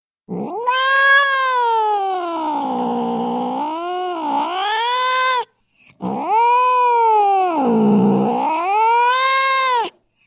На этой странице собраны звуки кошачьих драк – от яростного шипения до громких воплей и топота лап.
Злобное мяуканье кота